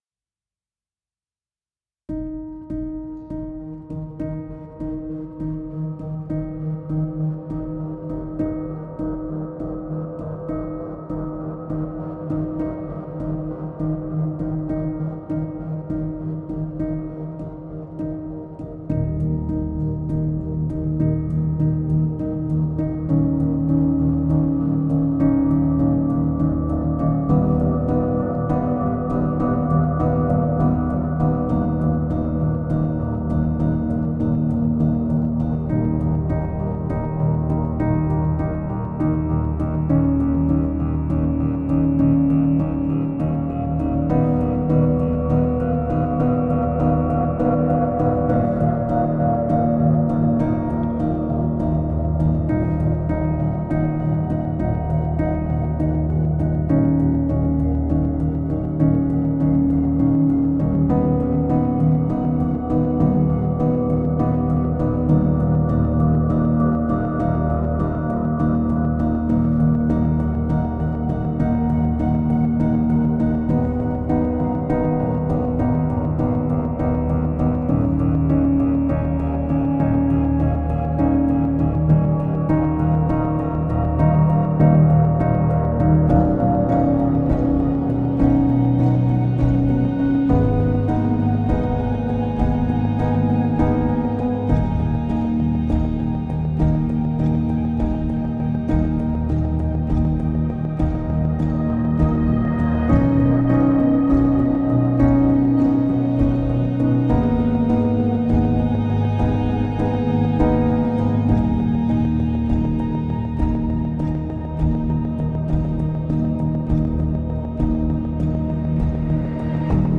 No Piano Melody